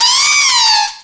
pokeemerald / sound / direct_sound_samples / cries / servine.aif